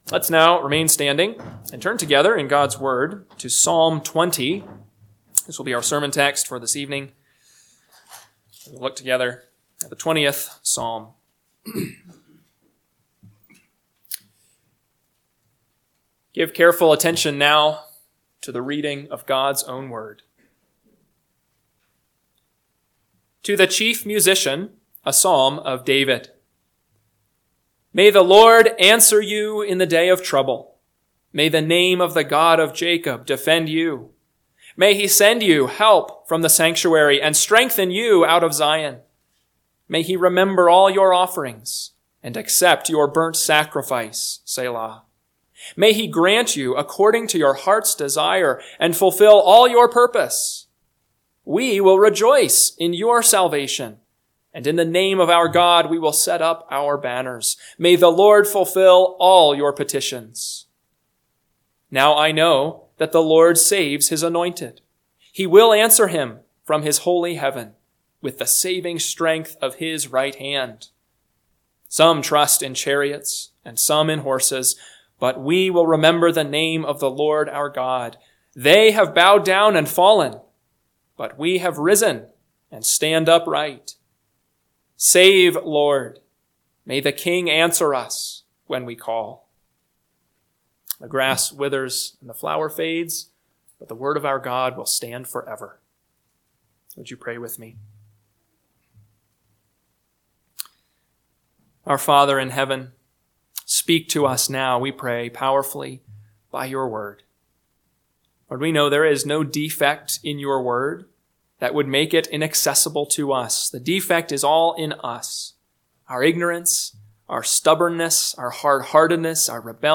PM Sermon – 1/19/2025 – Psalm 20 – Northwoods Sermons